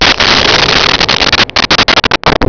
Sfx Amb Scrape Metal 02
sfx_amb_scrape_metal_02.wav